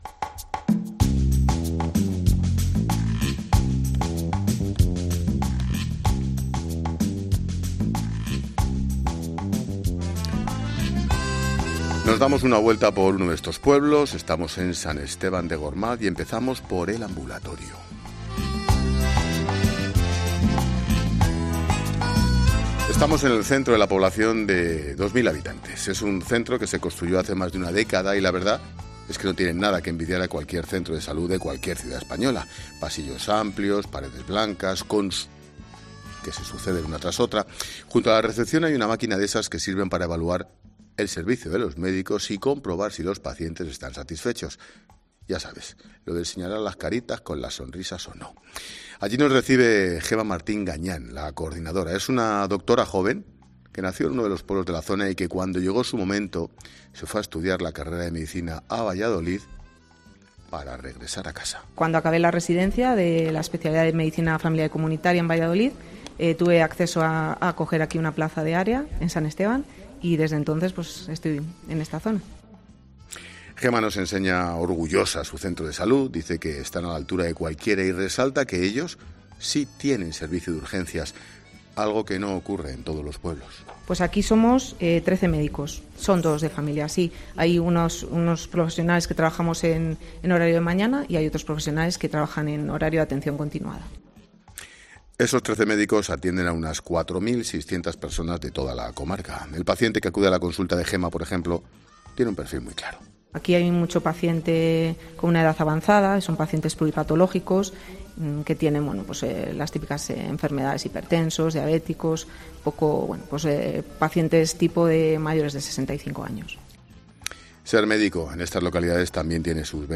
Un ejemplo perfecto de lo que supone ser paciente en la sistema sanitario de la 'España Vaciada' Hoy 'La Linterna' se enciende desde San Esteban de Gormaz, en la provincia de Soria. Pone el foco sobre la España de la que nadie habla, la que se va vaciando poco a poco.